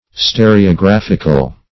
\Ste`re*o*graph"ic*al\, a. [Cf. F. st['e]r['e]ographique.]